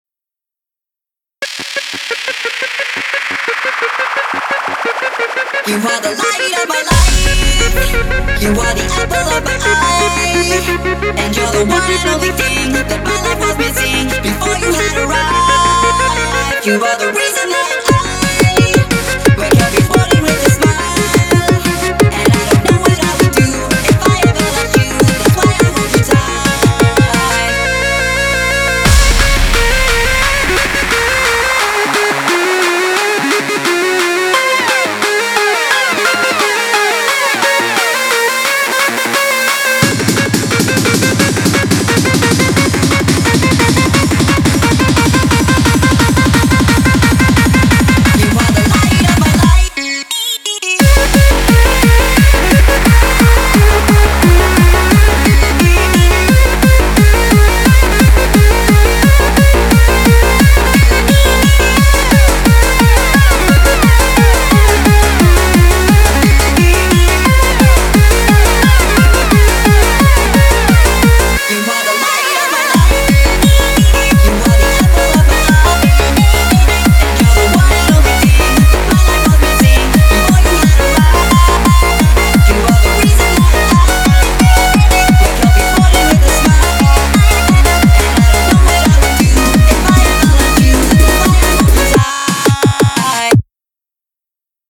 BPM88-175